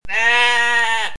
Sheep